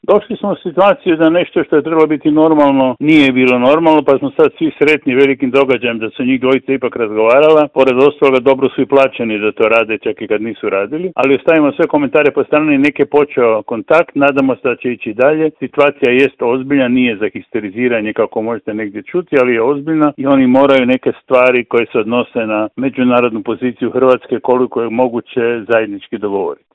Razgovor je za Media servis komentirao politički analitičar Žarko Puhovski: